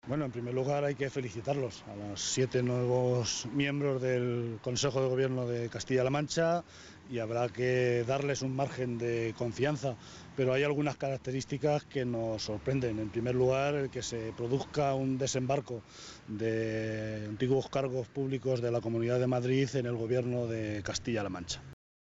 José Luis Martínez Guijarro, portavoz del Grupo Parlamentario Socialista.
Cortes de audio de la rueda de prensa